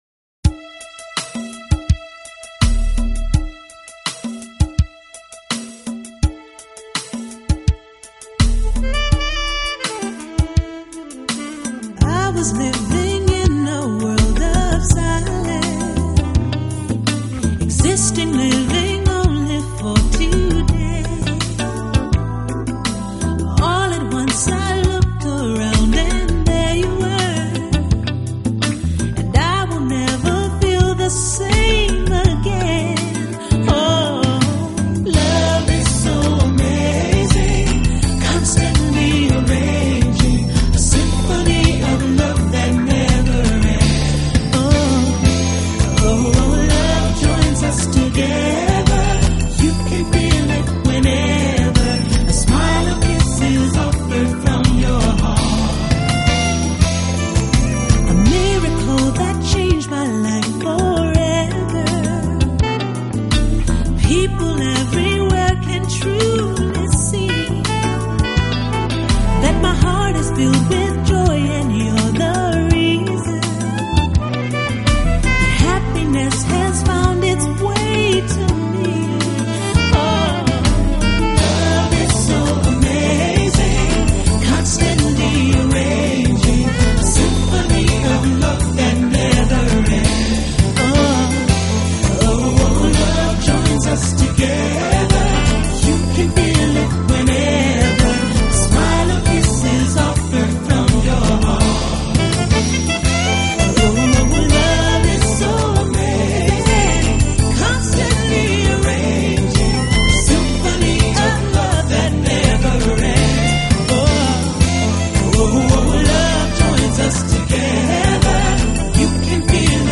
【爵士萨克斯】
音乐风格: Smooth, Jazz.